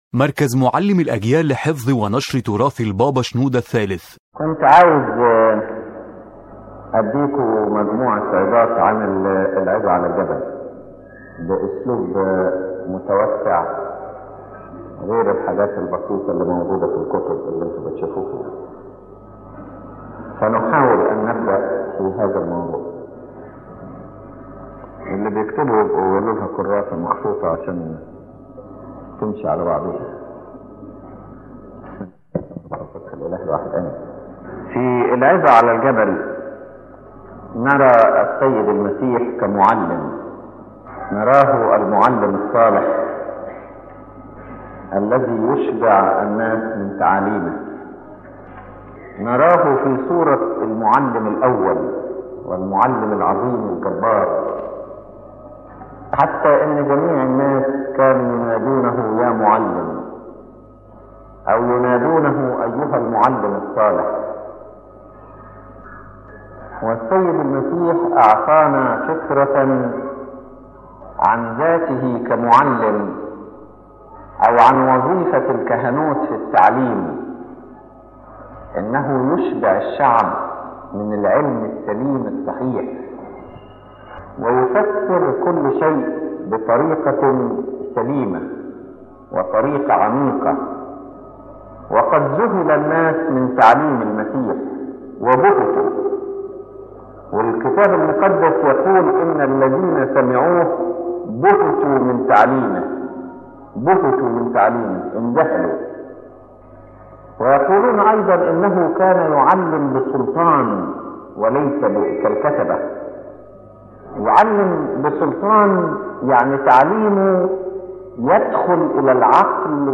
His Holiness Pope Shenouda III speaks in this sermon about the Lord Jesus as the divine and supreme teacher, whose teaching entered both the mind and the heart with authority.